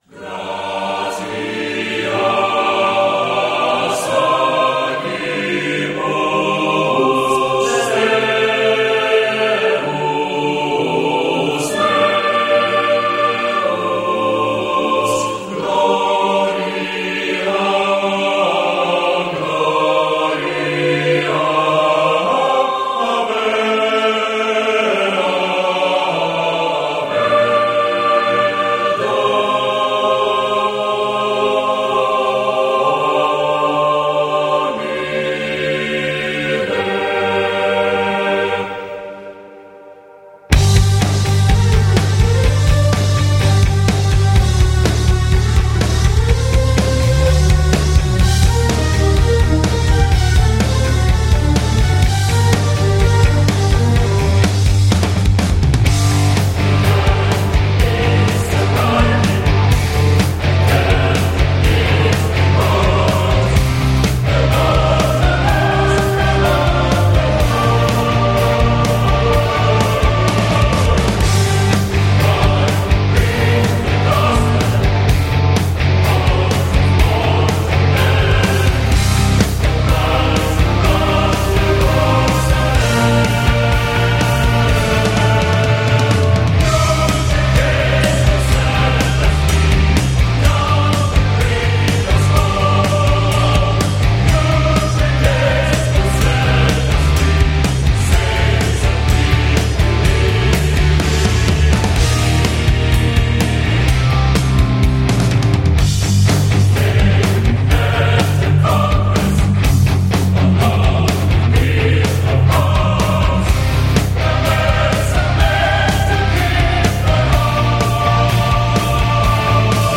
本期音乐为交响金属音乐专题。在本期音乐的选曲中，很多乐队的作品都充满着很多先锋实验色彩。